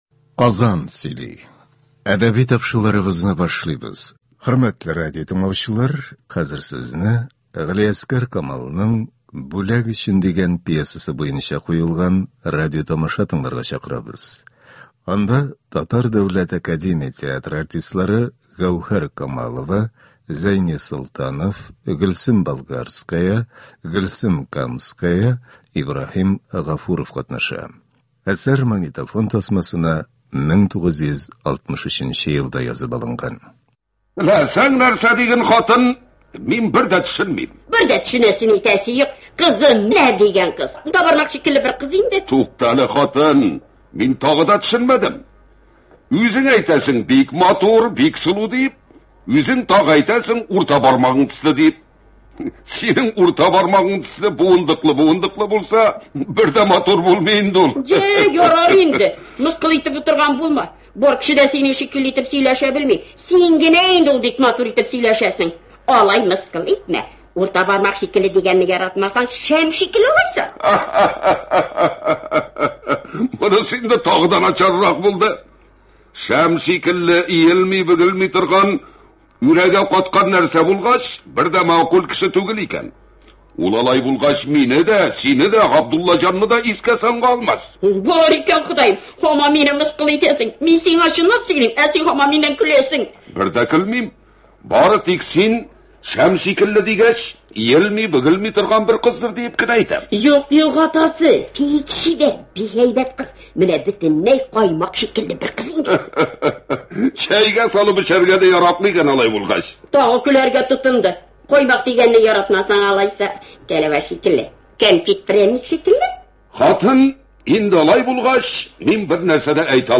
Галиәсгар Камал. “Бүләк өчен”. Радиопостановка.
Әсәр магнитофон тасмасына 1963 нче елда язып алынган.